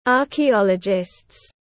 Nhà khảo cổ học tiếng anh gọi là archaeologists, phiên âm tiếng anh đọc là /ˌɑːrkiˈɑːlədʒɪst/.
Archaeologists /ˌɑːrkiˈɑːlədʒɪst/